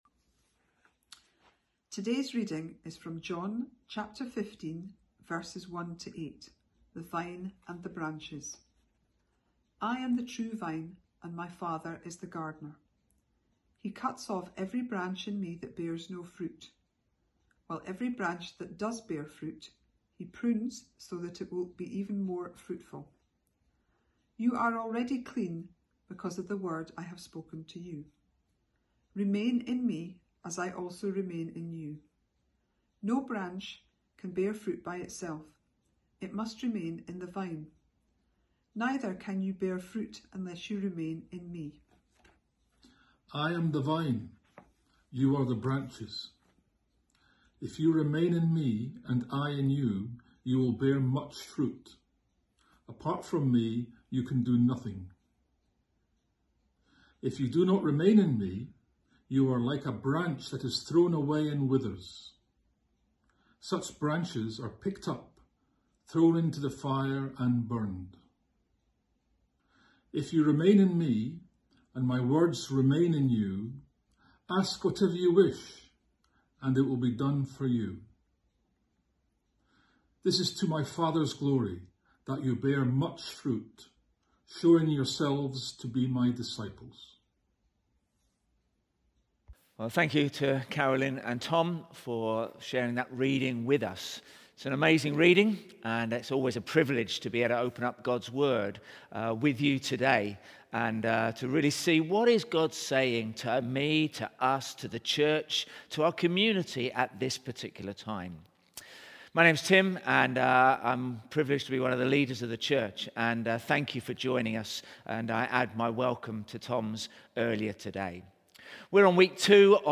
In light of this, we are inviting our congregation and community to join us live on Sundays online.